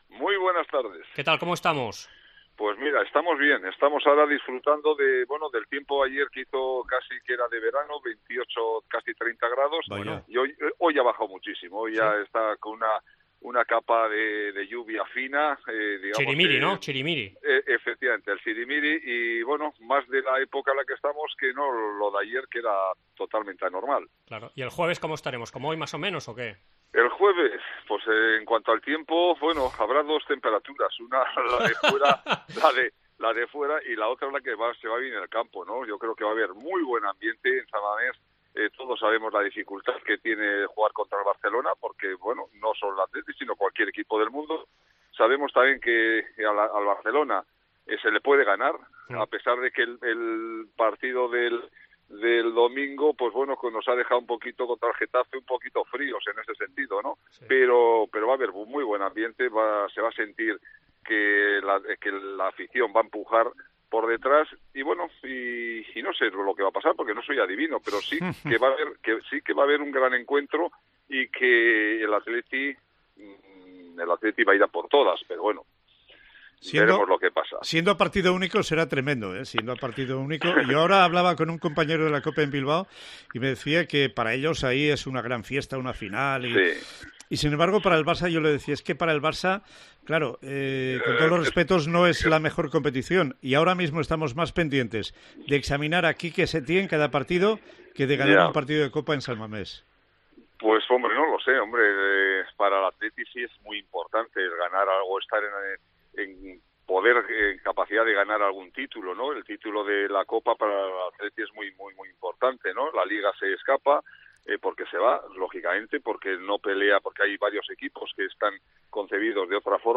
AUDIO: Entrevista a Andoni Goikoetxea, ex jugador del Athletic Club y ex compañero de Quique Setién en el Atlético de Madrid